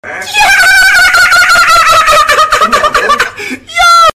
Flight Reacts Dolphin Laugh